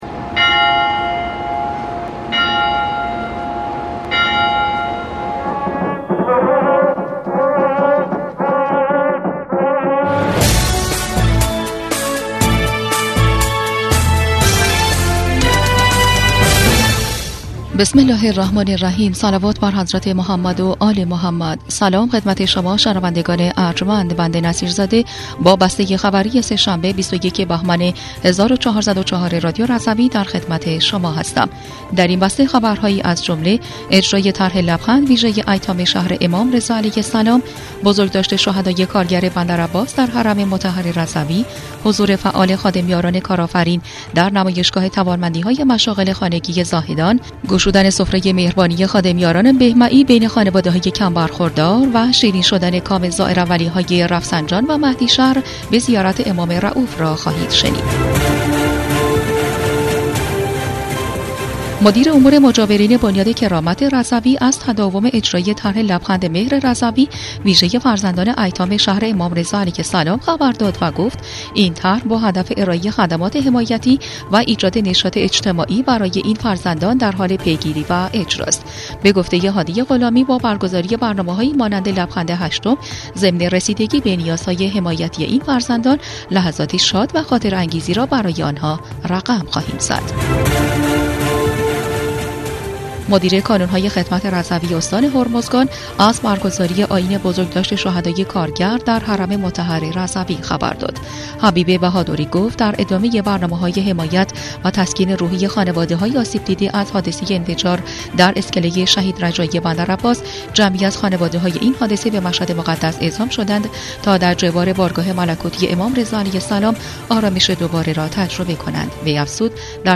بسته خبری ۲۱ بهمن ۱۴۰۴ رادیو رضوی؛